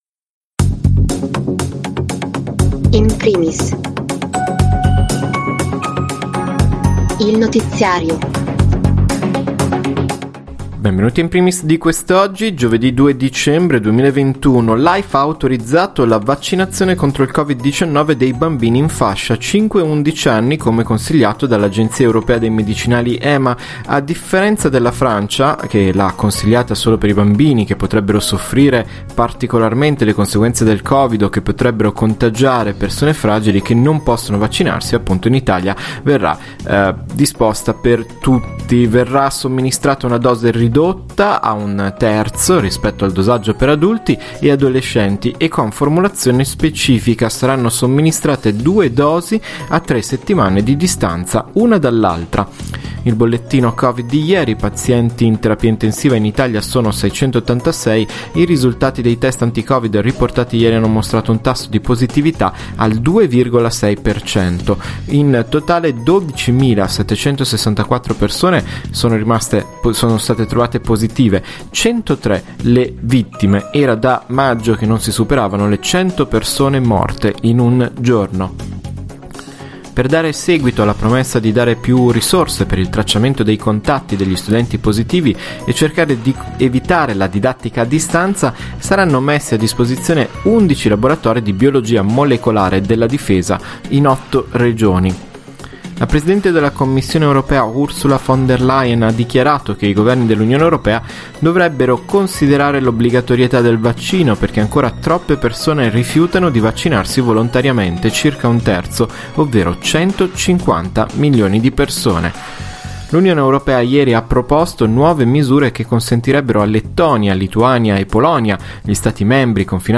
Notiziario del 2 dicembre